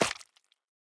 Minecraft Version Minecraft Version 1.21.5 Latest Release | Latest Snapshot 1.21.5 / assets / minecraft / sounds / block / beehive / drip5.ogg Compare With Compare With Latest Release | Latest Snapshot
drip5.ogg